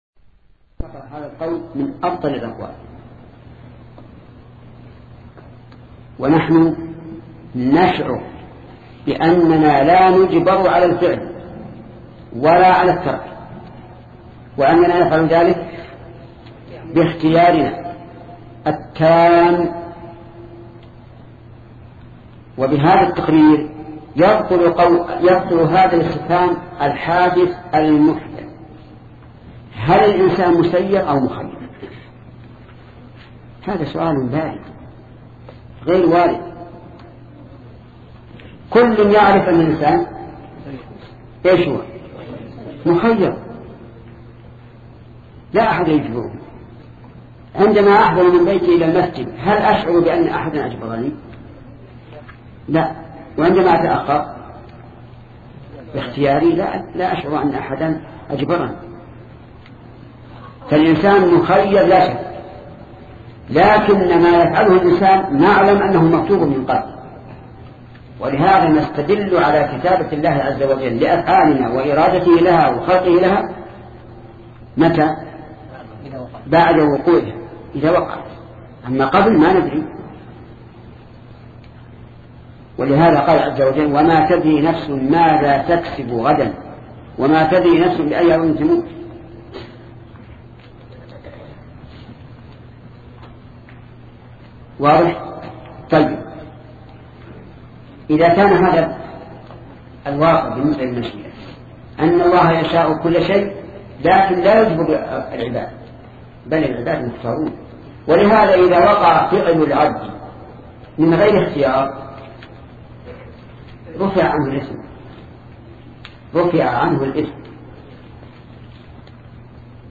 سلسلة مجموعة محاضرات شرح الأربعين النووية لشيخ محمد بن صالح العثيمين رحمة الله تعالى